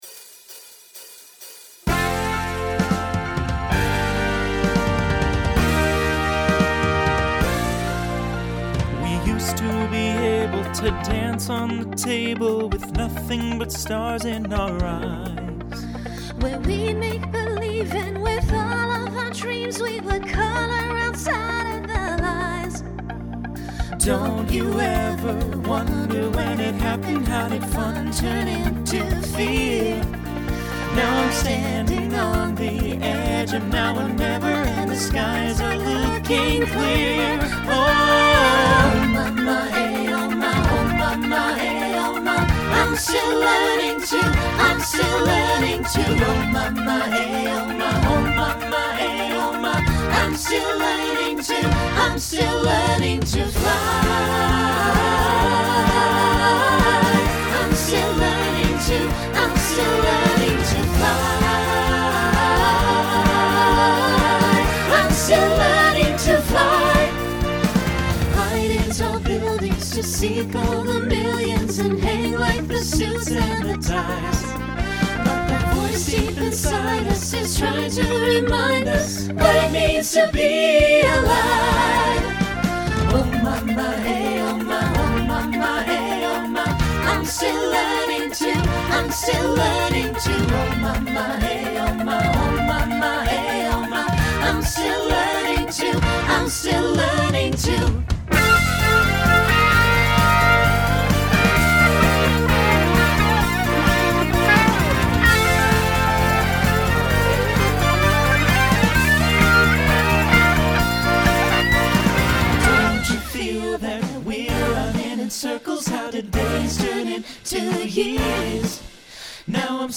Genre Rock Instrumental combo
Voicing SAB